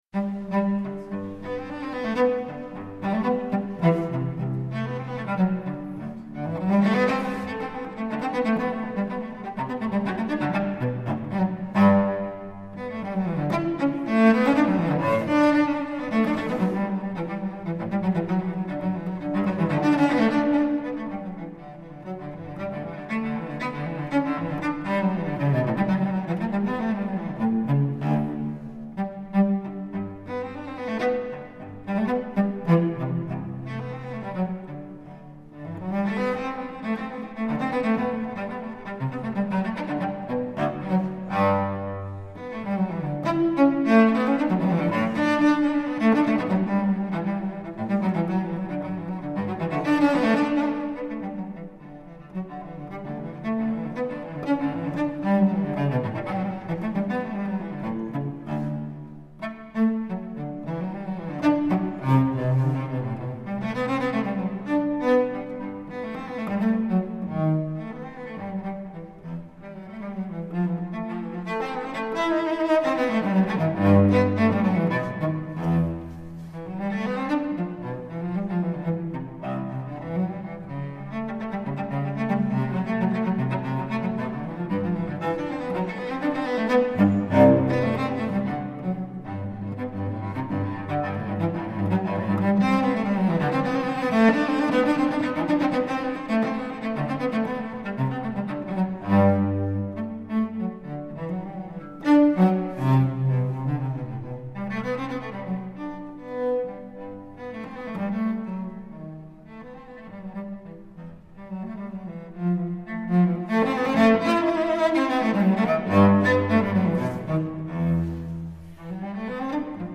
Incontro con Maximilian Hornung